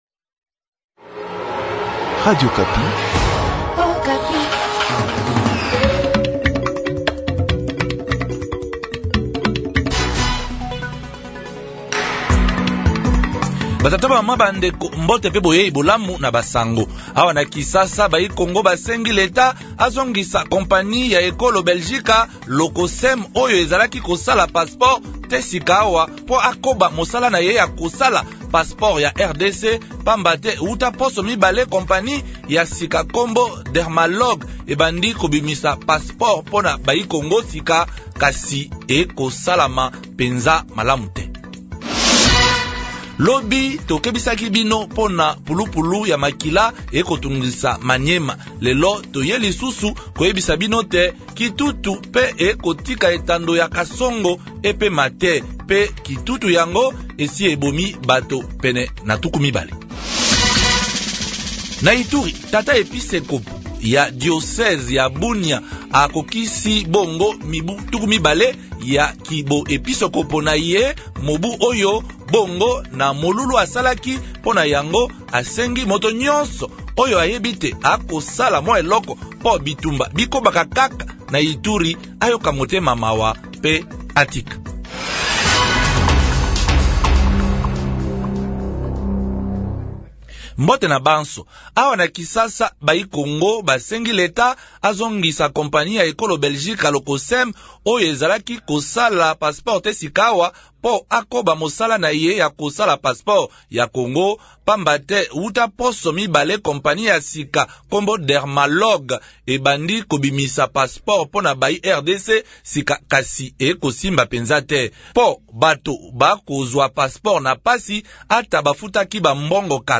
Journal lingala soir